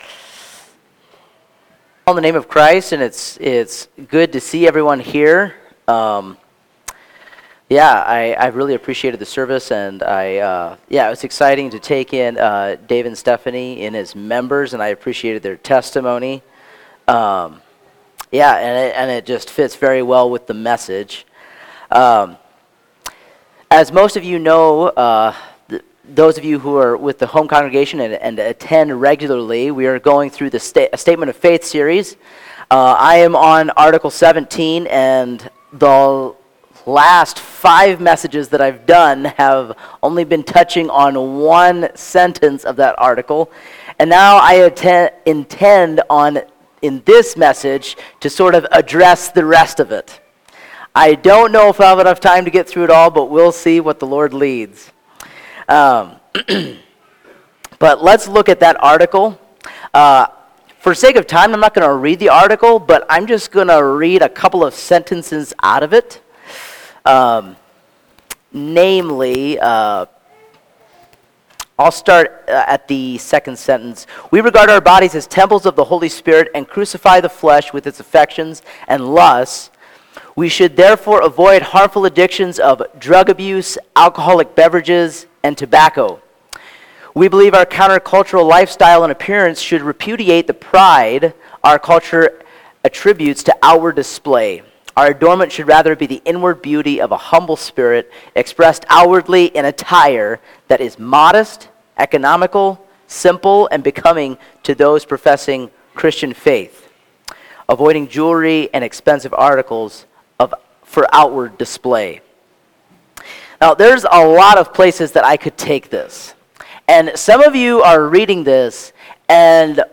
Home Sermons Statement of Faith: Article 17 Kingdom Living Defined?